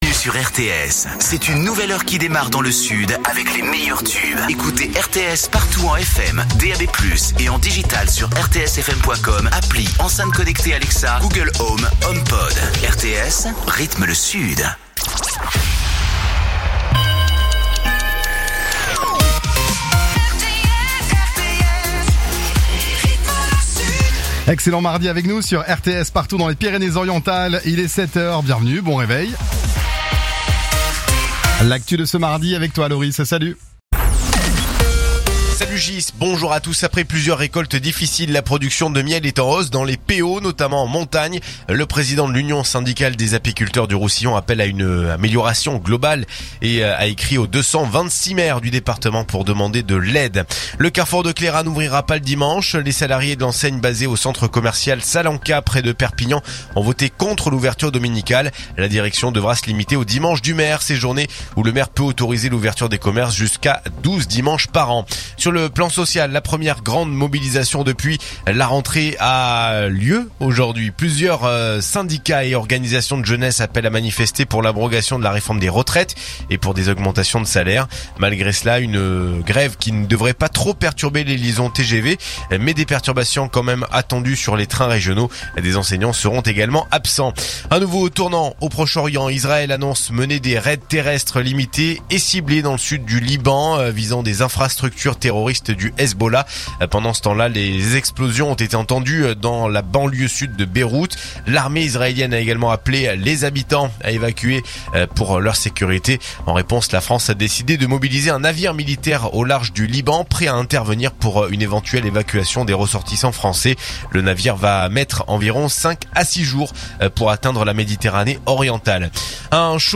Écoutez les dernières actus de Perpignan en 3 min : faits divers, économie, politique, sport, météo. 7h,7h30,8h,8h30,9h,17h,18h,19h.